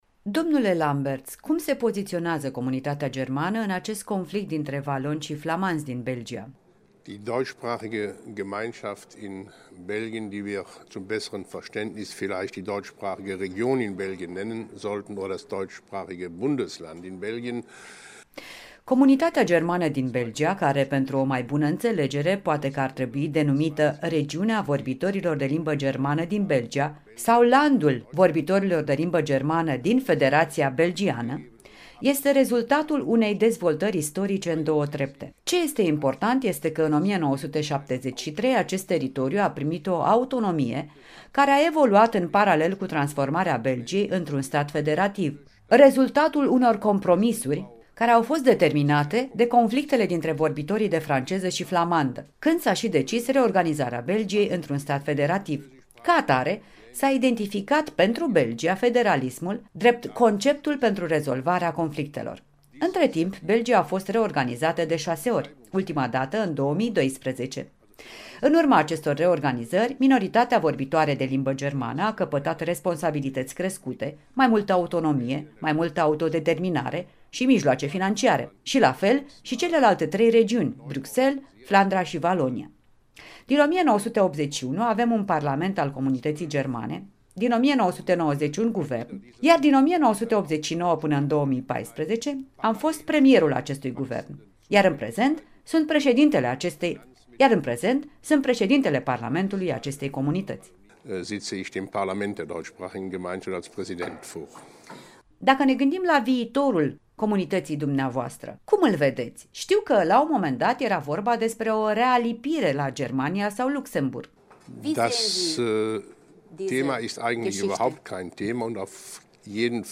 Interviu cu Karl-Heinz Lambertz